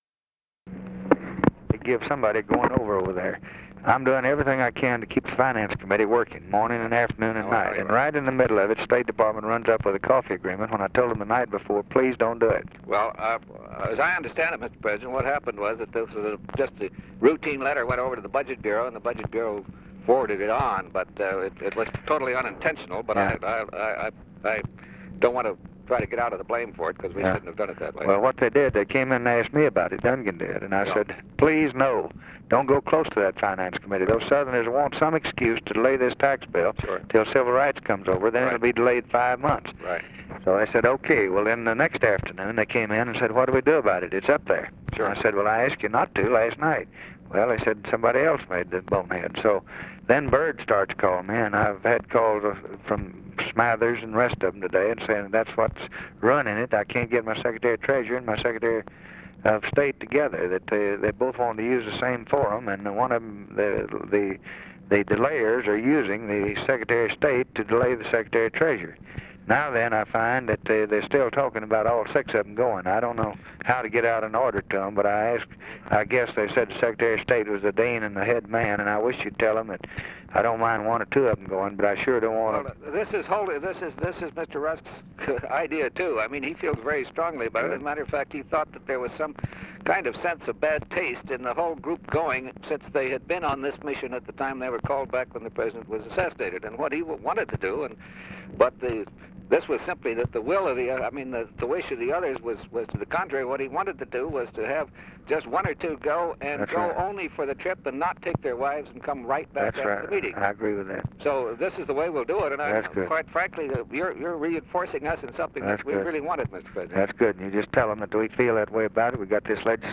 Conversation with GEORGE BALL, December 13, 1963
Secret White House Tapes